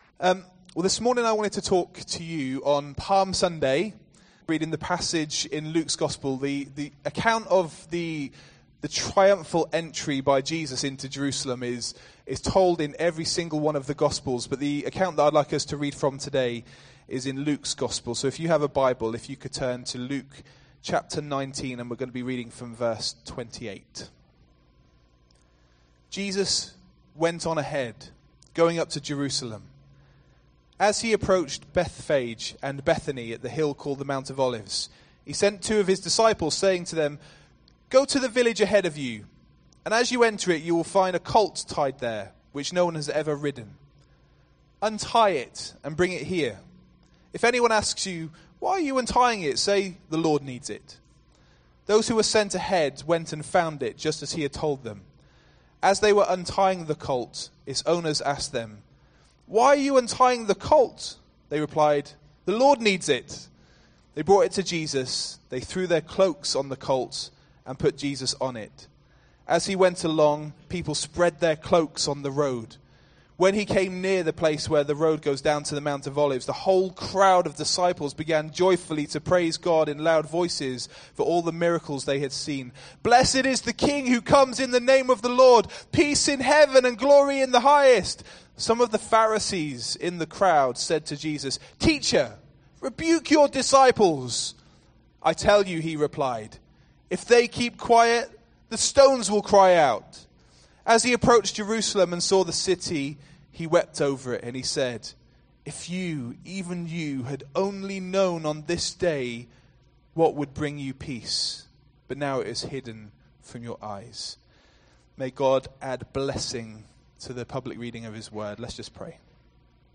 Throughout scripture we read that praise is an important facet of our relationship with God. In this passionate sermon